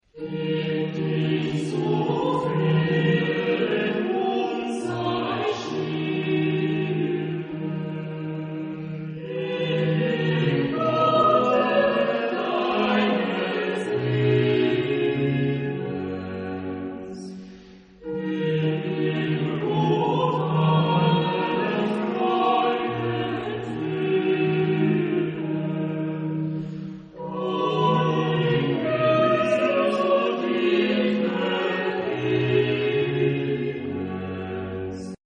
Genre-Style-Form: Sacred ; Baroque ; Choir
Type of Choir: SATB  (4 mixed voices )
Tonality: E minor